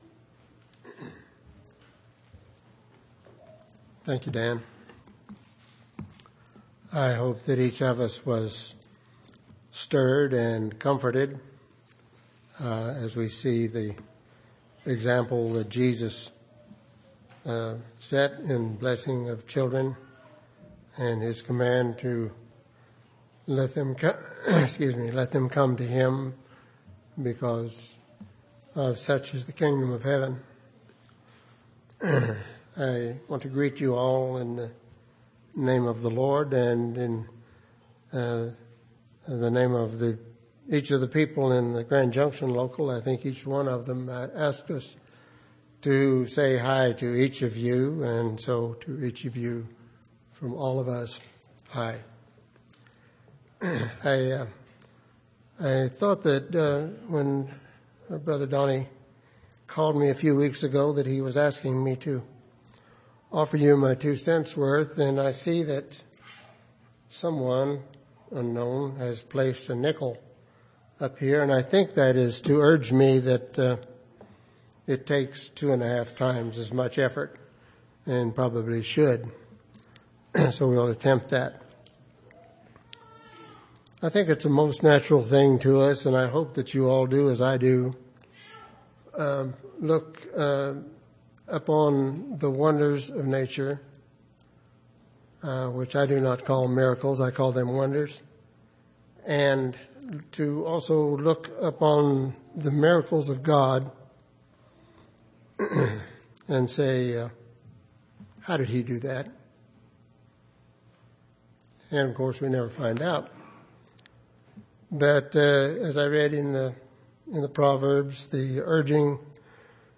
9/19/2010 Location: Temple Lot Local Event